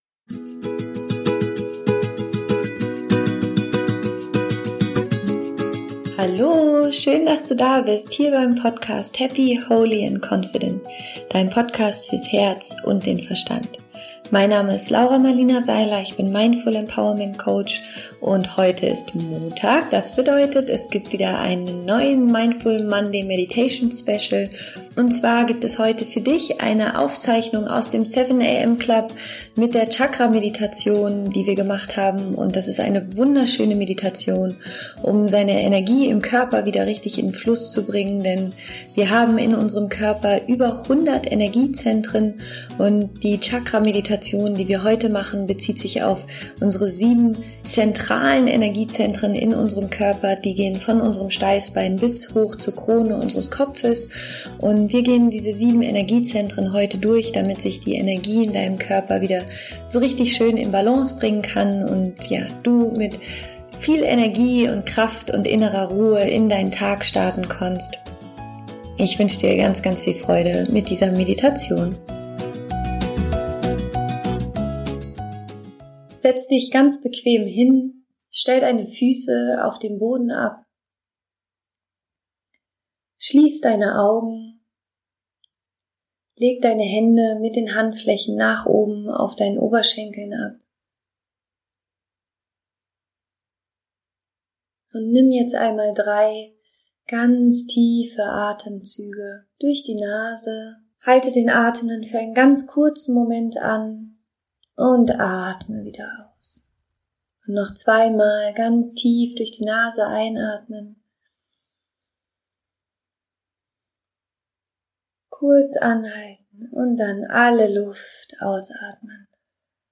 Chakra Meditation für mehr Energie